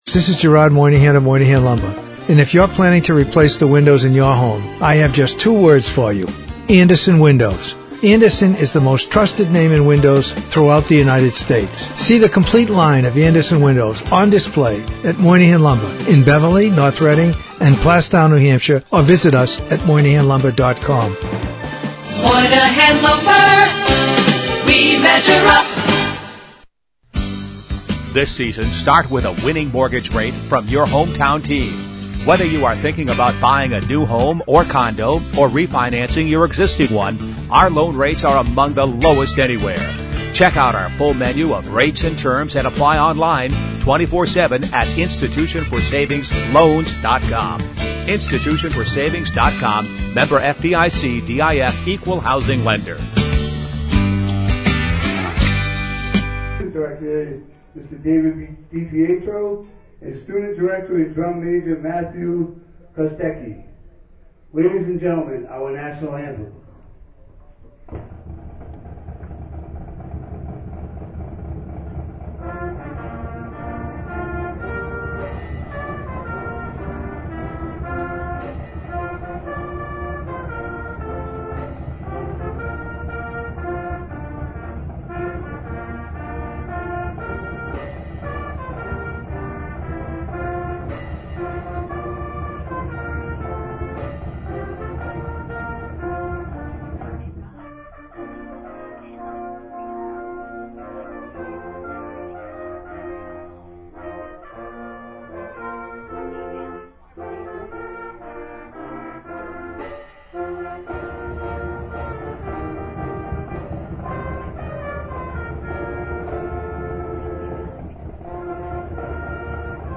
Game broadcast OnDemand